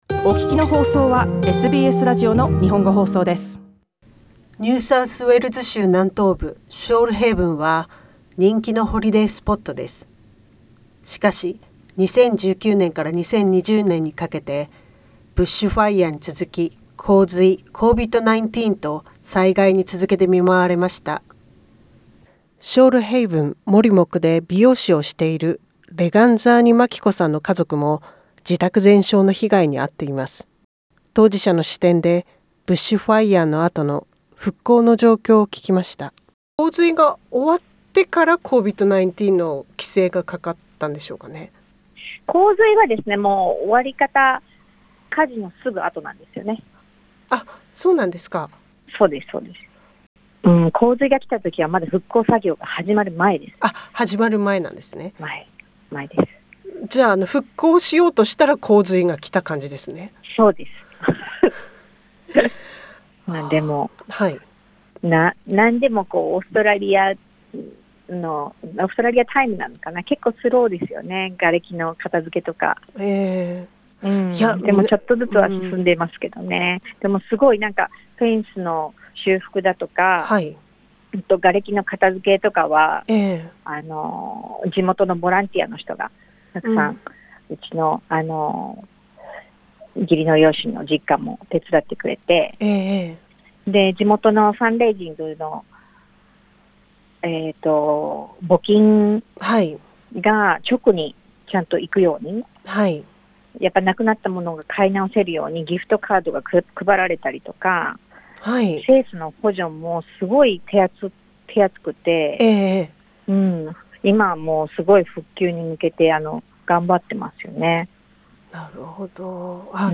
READ MORE 生死を分けた山火事 ショールヘイブンの夏 2019-2020 インタビューの内容は2020年6月中旬時点の、一個人の主観に基づくものです。